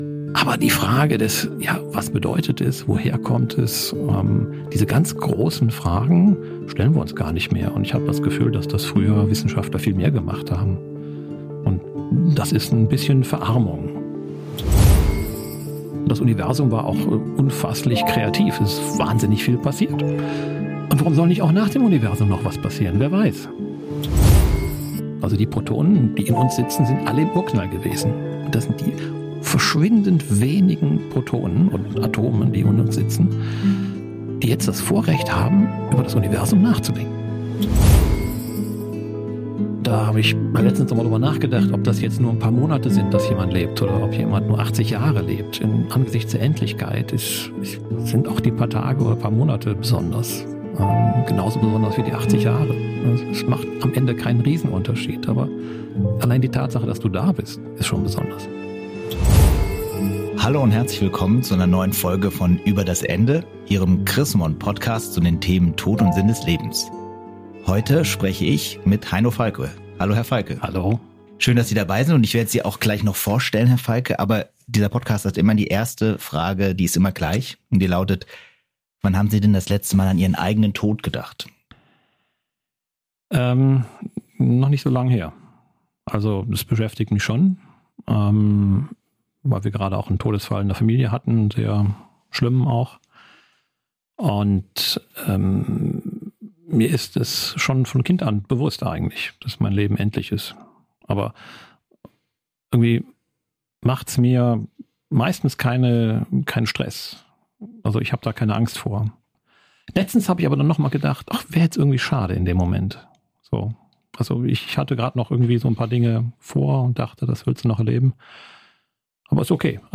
Im Gespräch geht es um den Anfang und das Ende der Welt und um den persönlichen Glauben und die Ängste von Herrn Falcke.